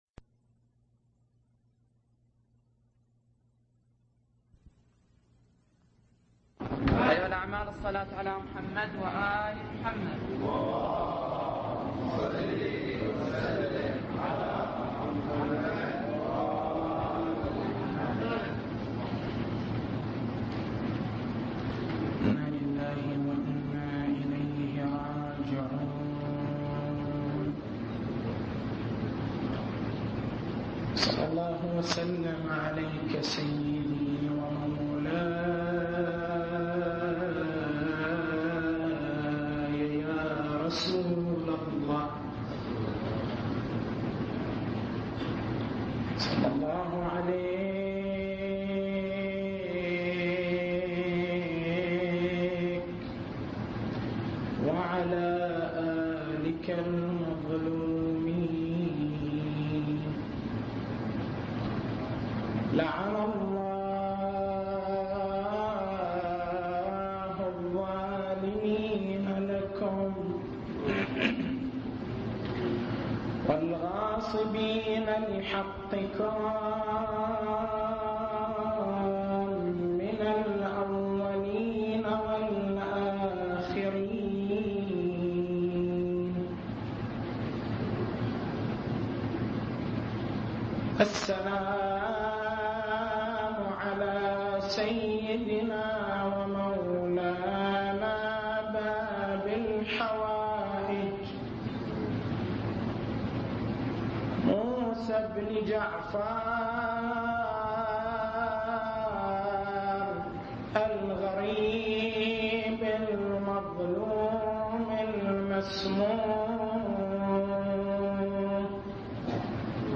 تاريخ المحاضرة: 25/07/1421 التسجيل الصوتي: شبكة الضياء > مكتبة المحاضرات > مناسبات متفرقة > أحزان آل محمّد